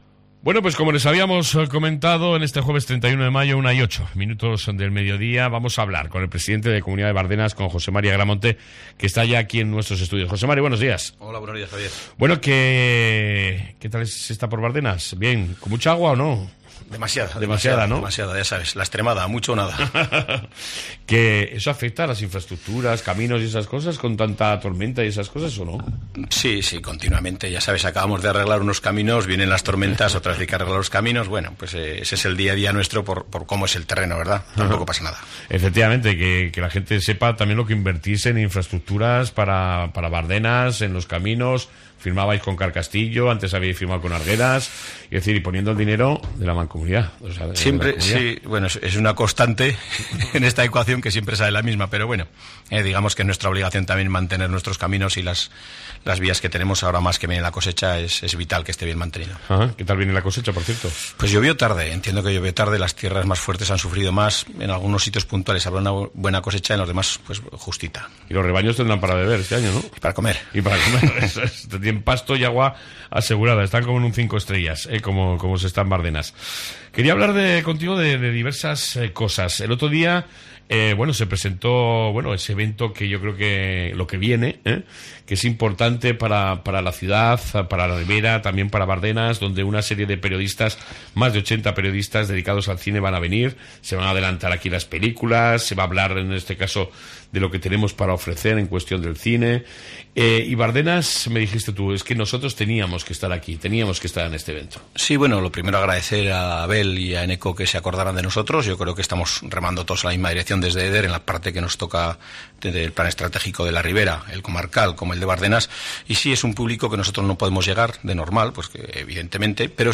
AUDIO: Hoy hemos recibido en Cope Ribera al al Presidente de la Comunidad de Bardenas Reales , Jose Mari Agramonte Aguirre, para hablar de diversos...